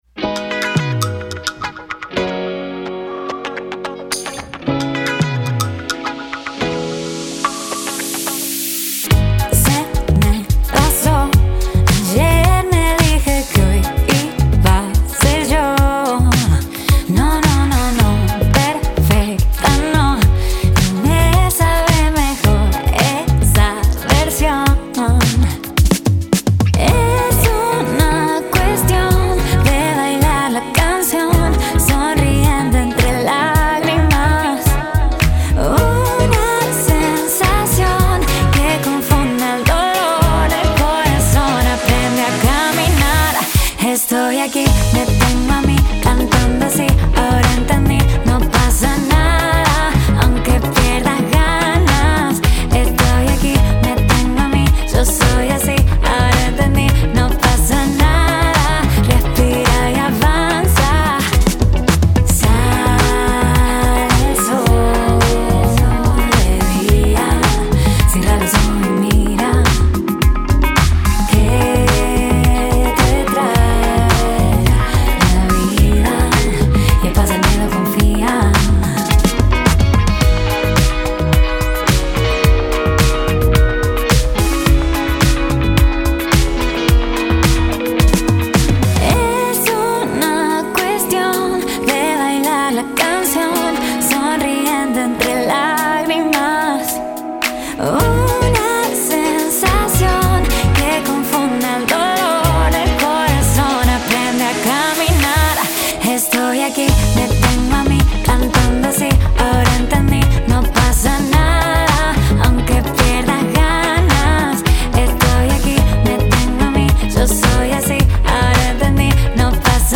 en Estudios Onceloops, Buenos Aires, Argentina